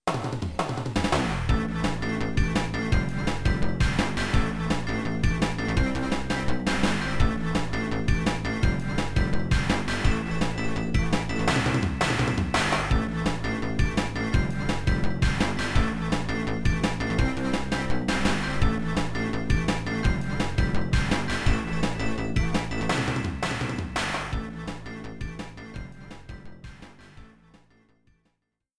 Boss theme